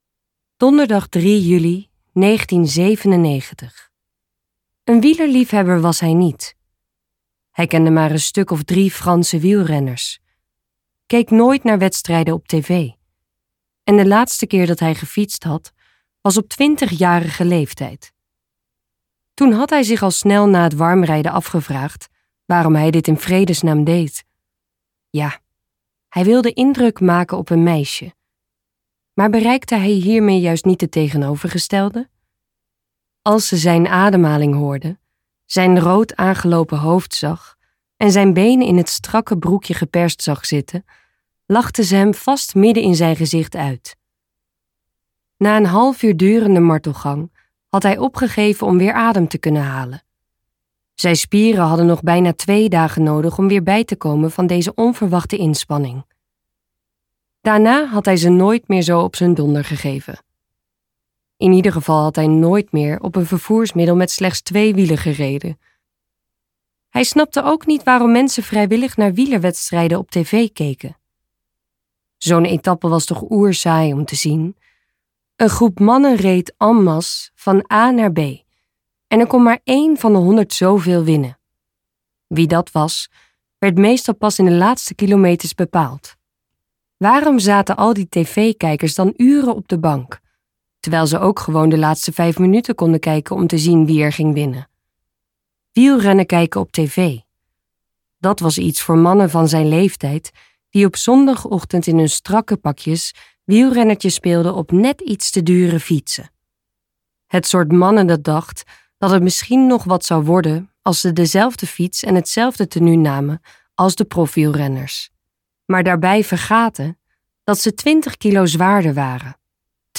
Luisterboek